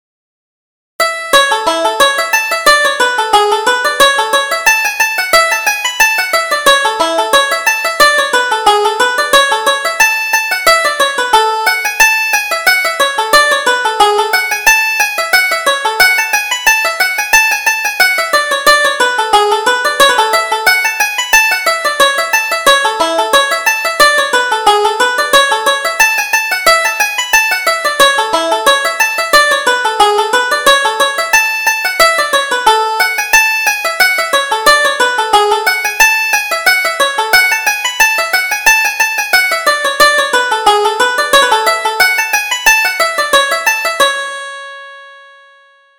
Reel: Dooley's Fancy